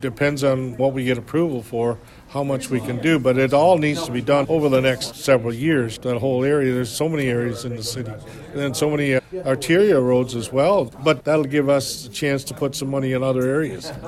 Mayor Jim Harrison says that could make a big impact on the work.